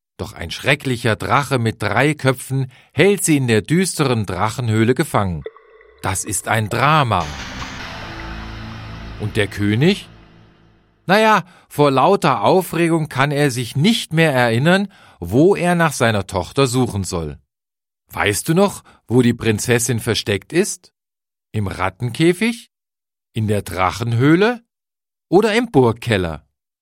interaktive Hörabenteuer zum Mitbestimmen
…sehr professionell produziert  (Forum Logopädie)
…professionelle Sprechweise des Vorlesers